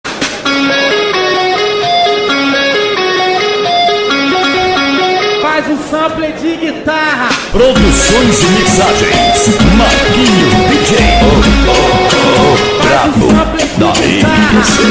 sampledeguitarratoque.mp3